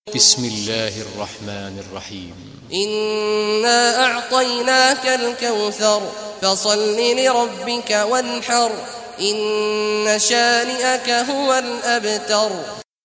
Surah Kausar Recitation by Sheikh Awad Juhany
Surah Kausar, listen or play online mp3 tilawat in the beautiful voice of Sheikh Abdullah Awad al Juhany.